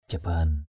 /ʥa-ba:n˨˩/ (d.) bệ, bàn thờ = table, autel. altar.